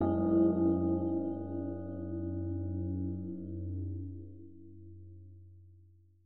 zen gong